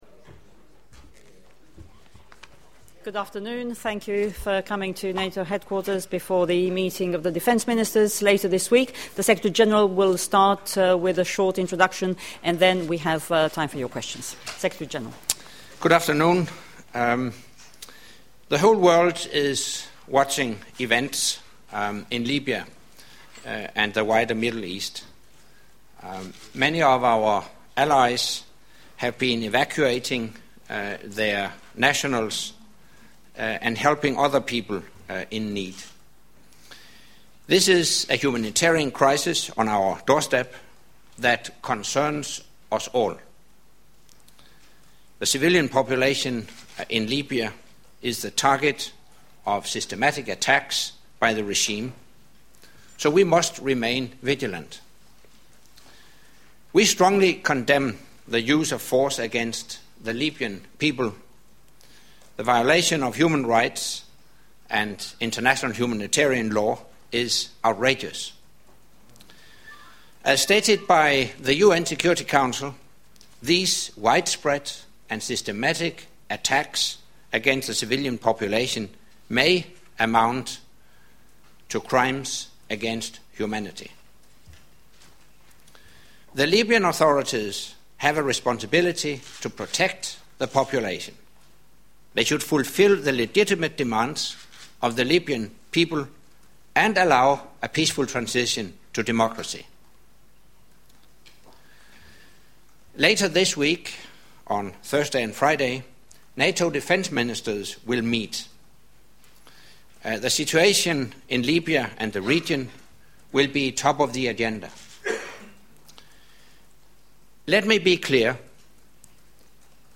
Secretary General's monthly press briefing - Pre–ministerial briefing - Monday 7 March 2011
Press Briefing by NATO Secretary General Anders Fogh Rasmussen preceding the March NATO Defence Ministerial Meeting - Opening remarks and Questions and Answers